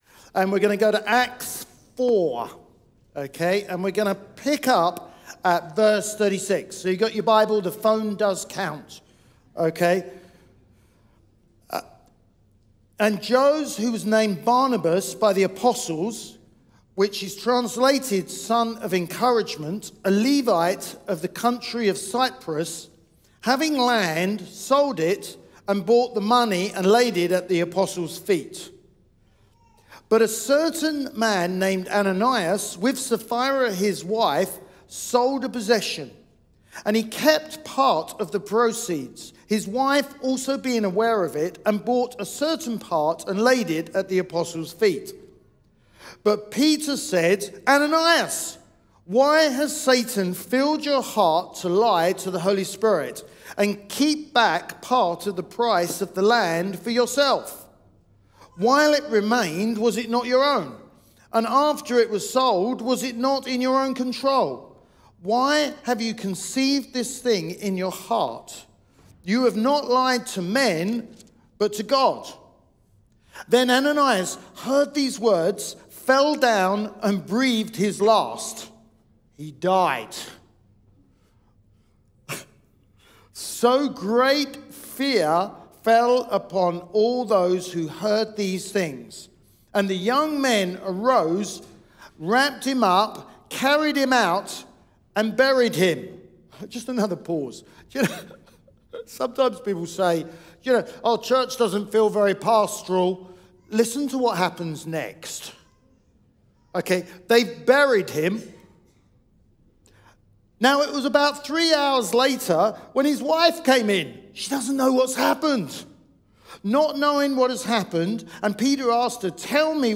Sunday Sermon Fear of the Lord
Live Stream